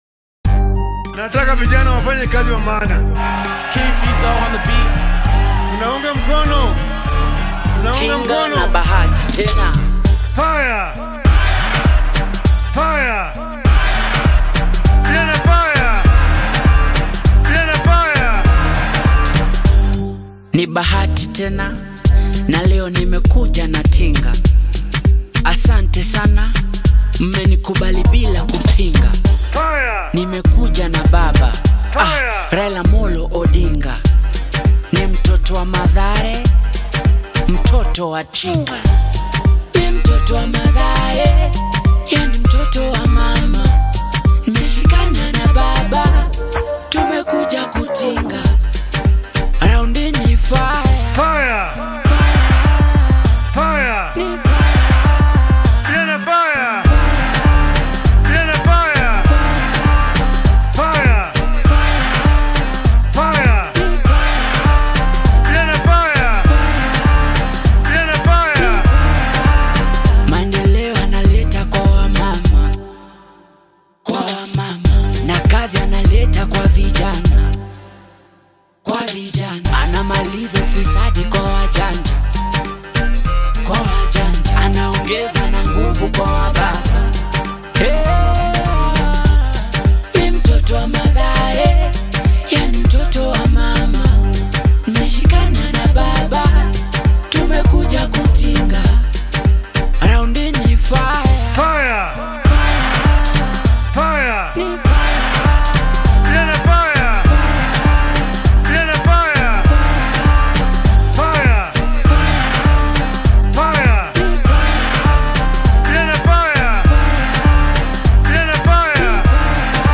political song